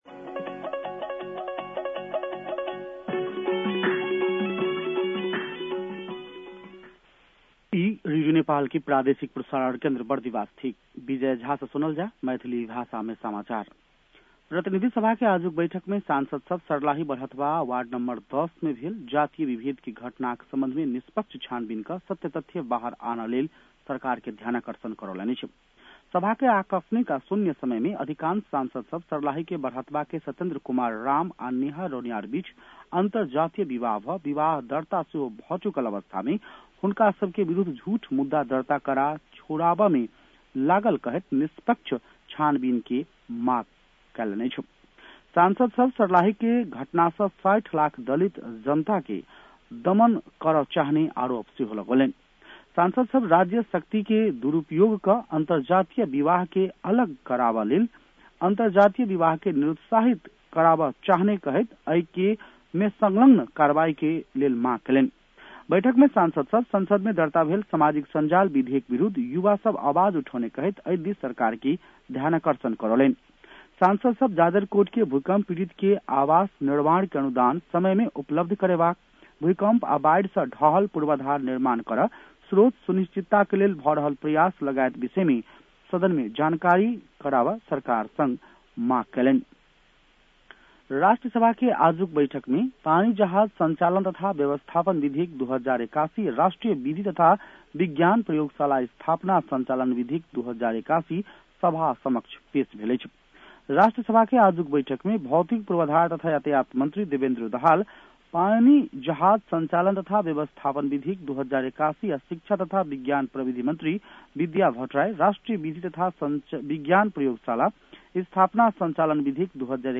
मैथिली भाषामा समाचार : २५ माघ , २०८१
Maithali-news-10-24.mp3